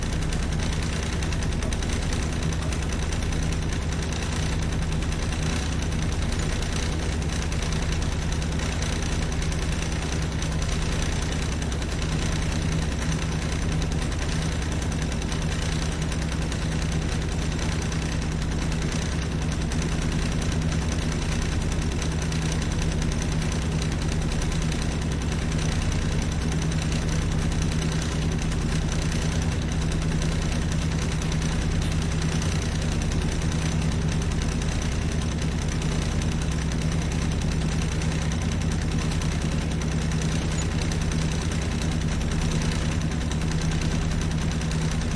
自助洗衣店 " 自助洗衣店的洗衣机 震动的响声1
描述：洗衣店洗衣机洗衣机拨浪鼓vibrate1.flac
Tag: 洗衣店 洗衣机 振动 拨浪鼓 洗涤